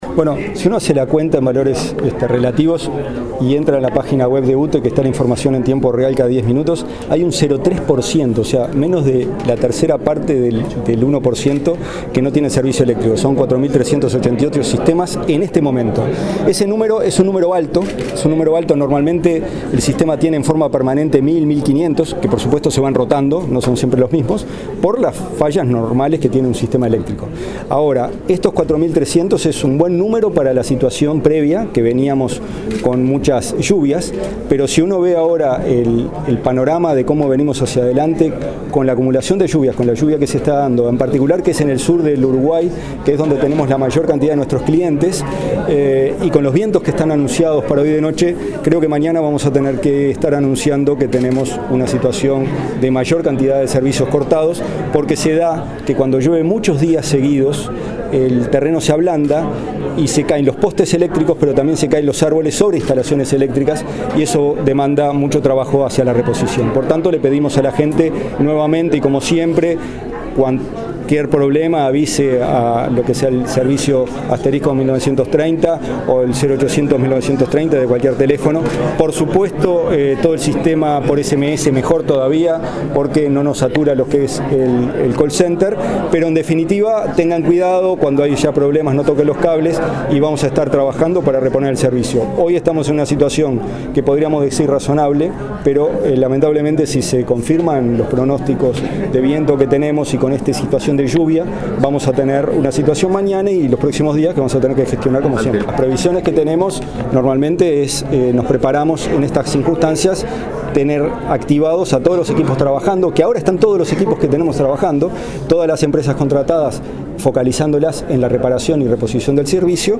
El presidente de UTE, Gonzalo Casaravilla, dijo que las lluvias afectarán los servicios eléctricos, hoy interrumpidos en un 0,3 %.